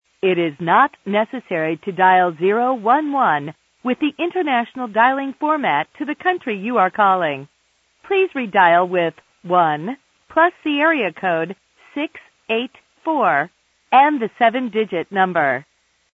AT&T General Information & Error Telephone Sounds & Recordings